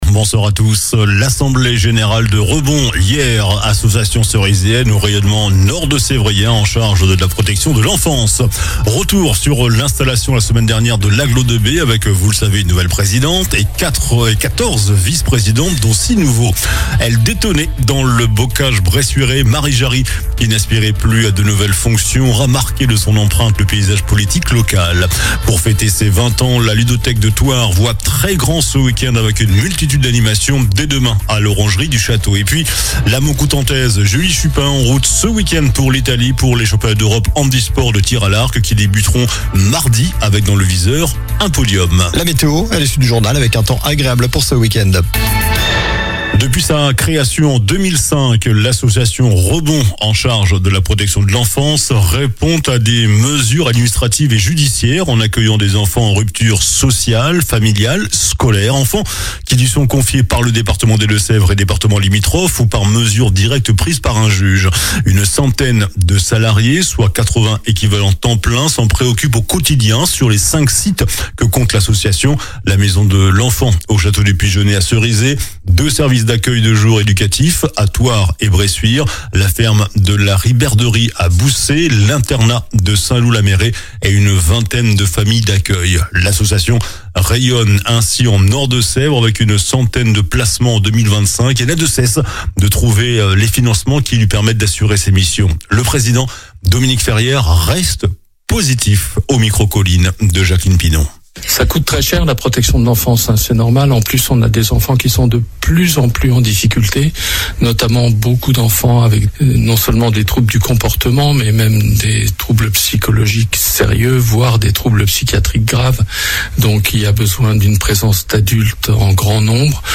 JOURNAL DU VENDREDI 24 AVRIL ( SOIR )